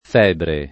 febre [ f $ bre ] → febbre